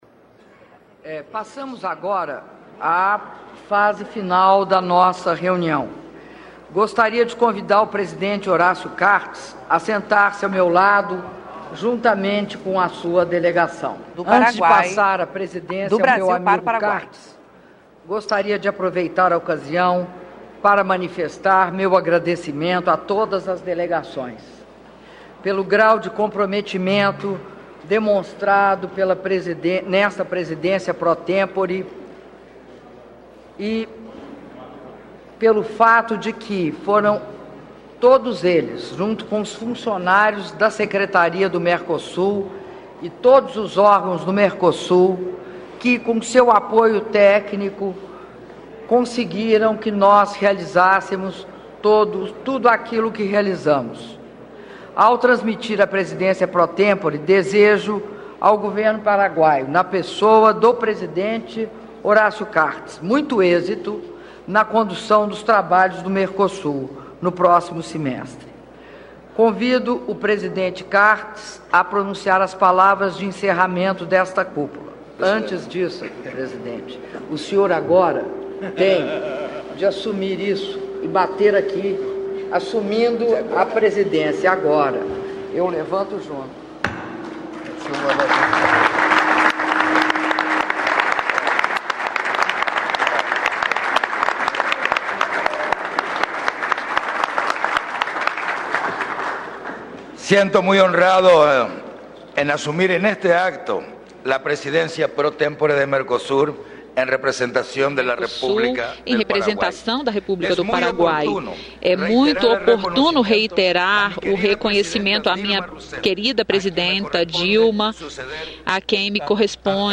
Áudio das palavras da Presidenta da República, Dilma Rousseff, durante encerramento da 48ª Cúpula do Mercosul - Brasília/DF (04min24s) — Biblioteca